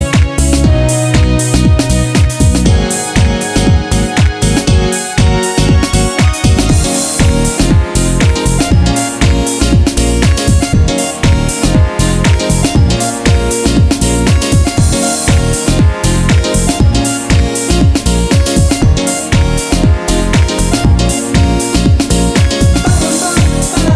Dance Mix Dance 4:13 Buy £1.50